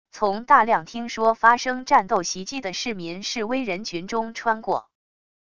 从大量听说发生战斗袭击的市民示威人群中穿过wav音频